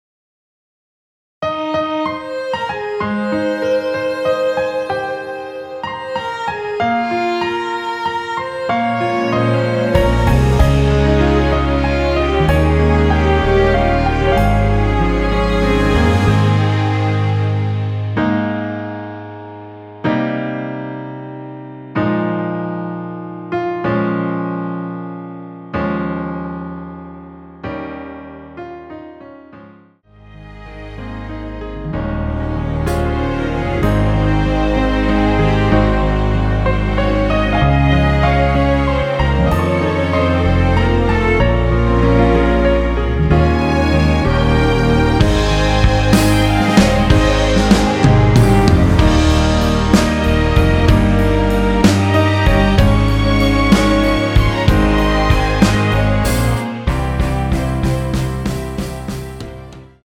원키에서(-3)내린 1절후 후렴으로 진행되는 MR입니다.
Ab
앞부분30초, 뒷부분30초씩 편집해서 올려 드리고 있습니다.
중간에 음이 끈어지고 다시 나오는 이유는